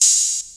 Crashes & Cymbals
MetroBomin Cym.wav